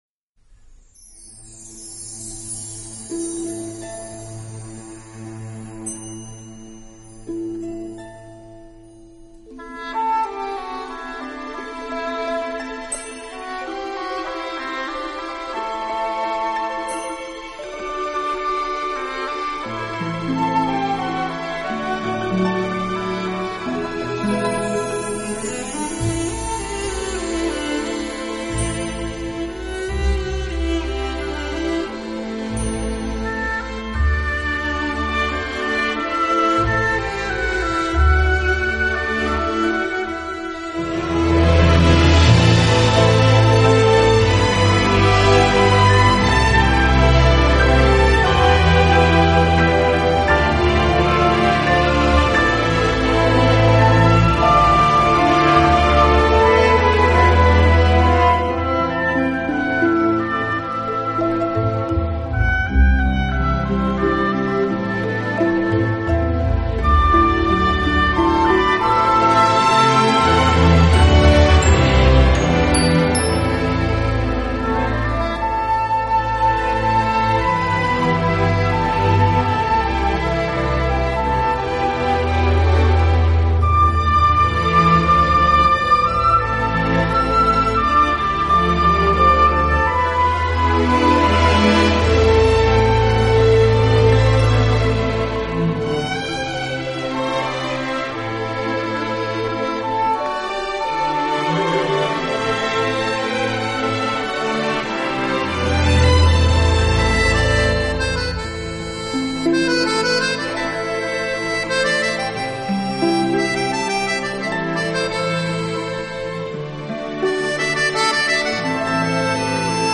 音乐类型: 电影音乐